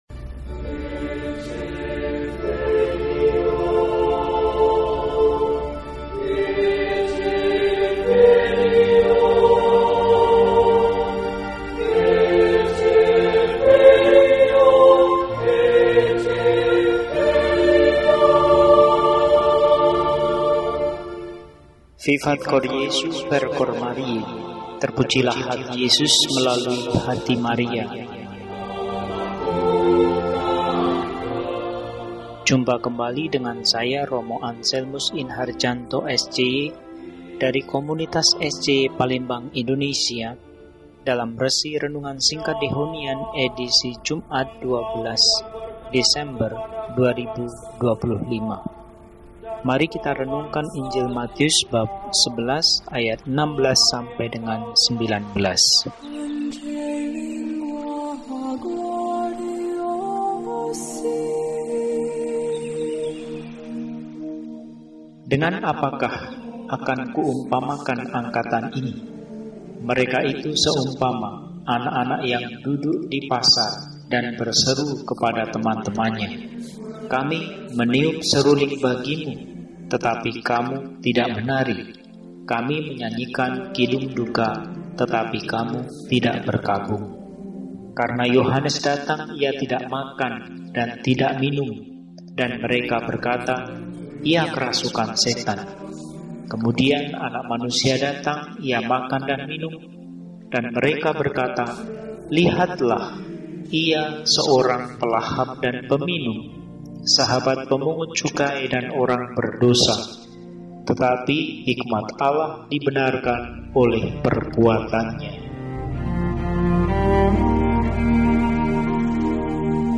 Jumat, 12 Desember 2025 – Hari Biasa Pekan II Adven – RESI (Renungan Singkat) DEHONIAN